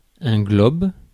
Ääntäminen
Synonyymit monde Ääntäminen France: IPA: [glɔb] Haettu sana löytyi näillä lähdekielillä: ranska Käännös Substantiivit 1. globe Suku: m .